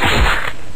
spikes.ogg